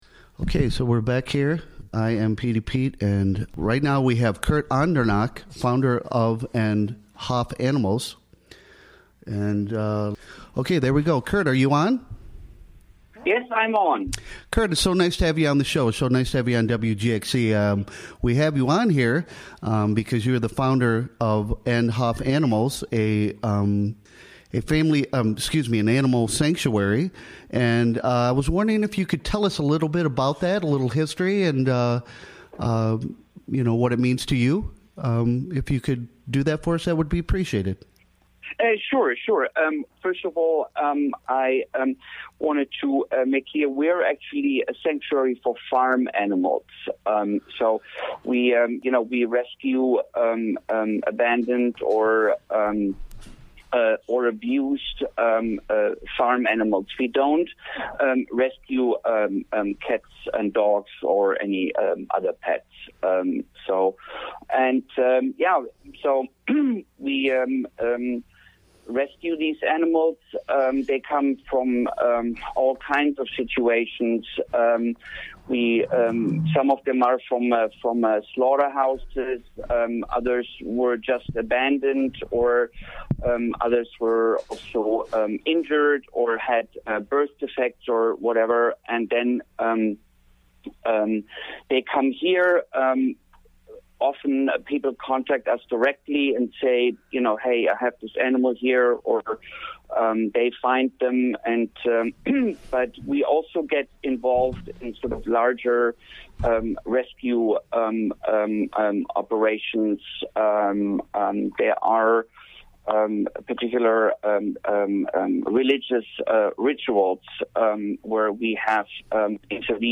Recorded during the WGXC Morning Show of Tues., Dec. 12.